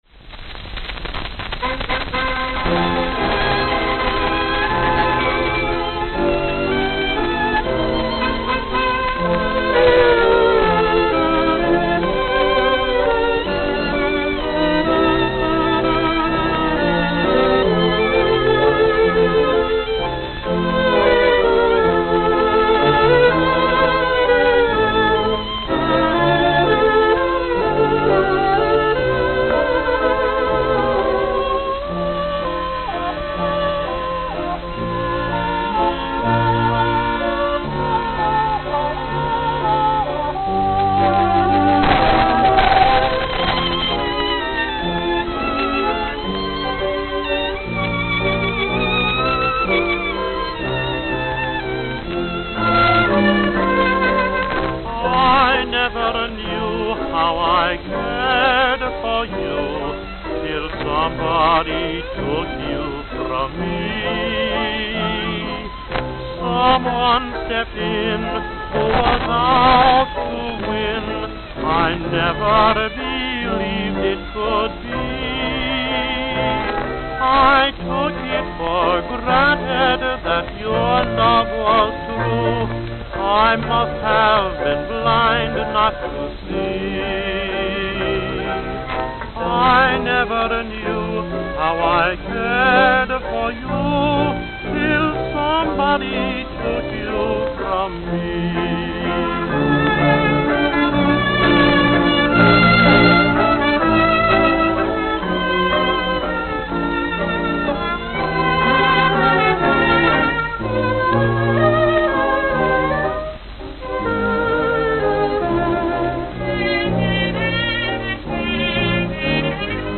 Played at 78 RPM.